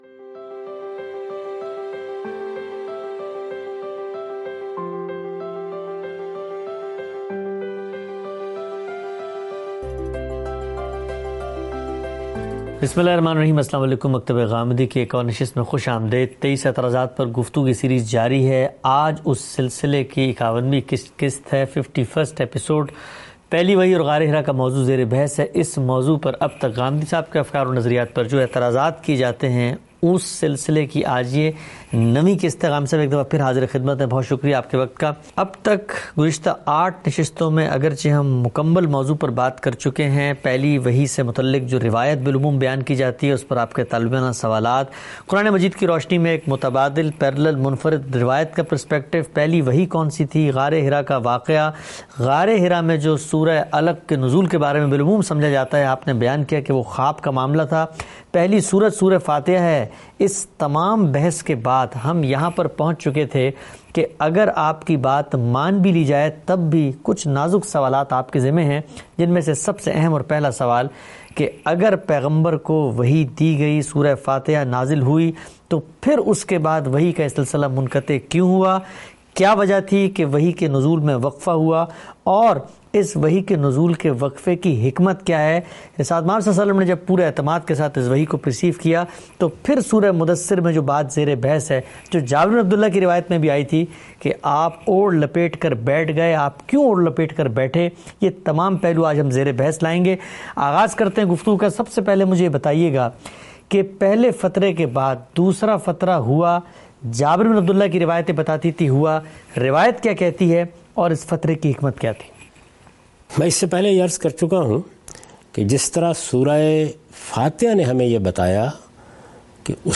In this video, Mr Ghamidi answers questions